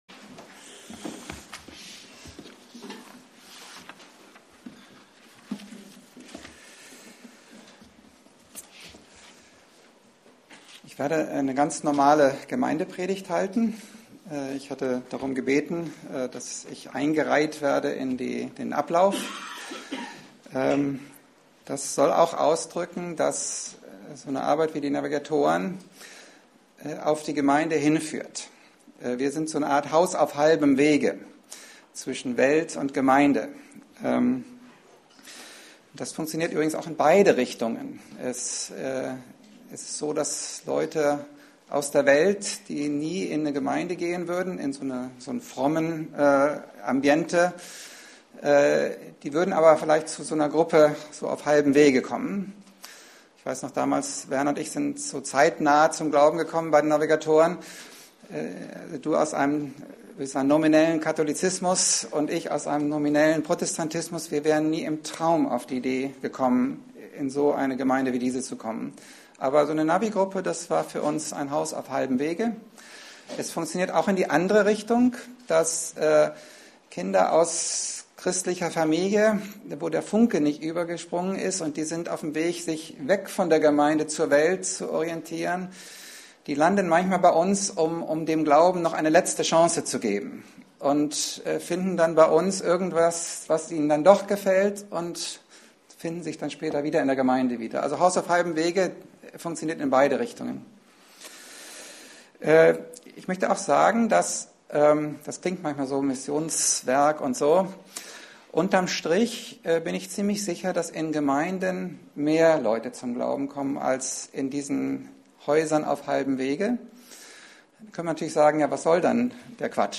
Dienstart: Predigt